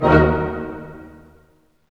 HIT ORCHM07R.wav